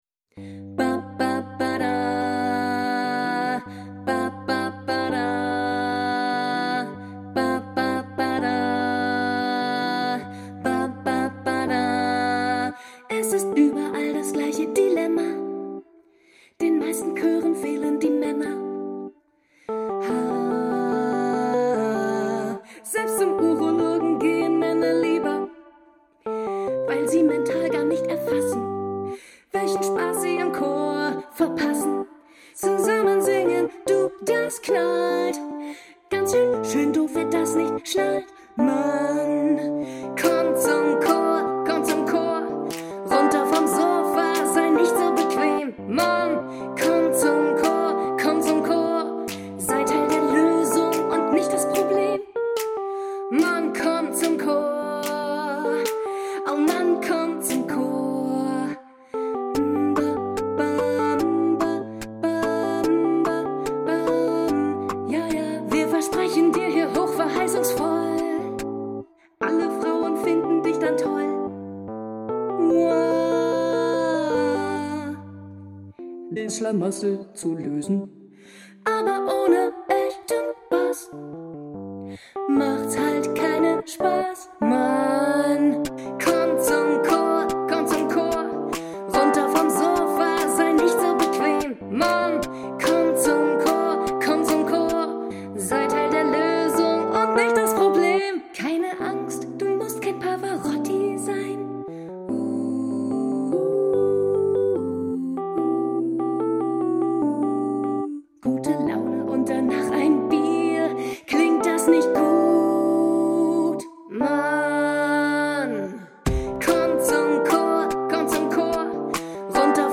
• Besetzung: 4-stimmig, a cappella
Altstimme